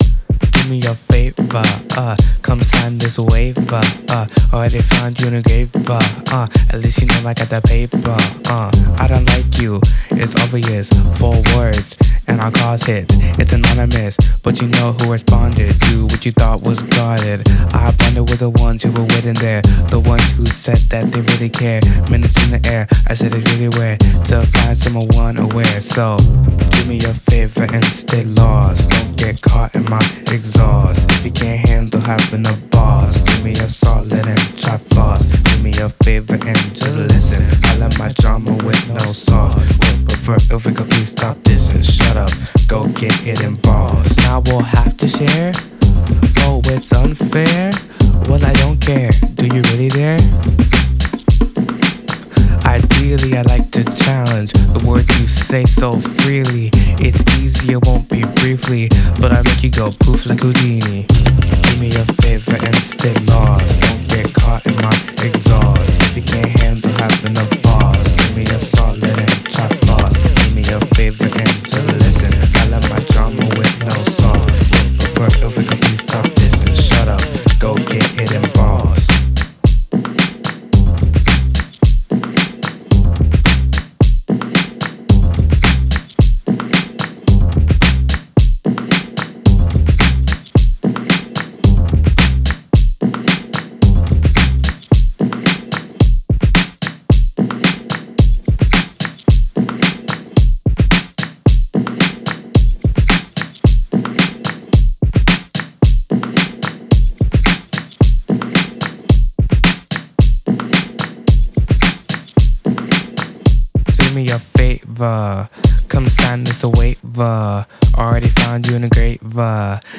showcasing influences from hip-hop and rap genres.